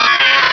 pokeemerald / sound / direct_sound_samples / cries / sableye.aif